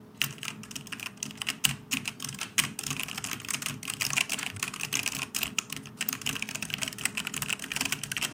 Fast typing on a keyboard
fast Keyboard OWI spelling typing sound effect free sound royalty free Memes